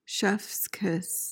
PRONUNCIATION: (chefs KIS) MEANING: noun: 1.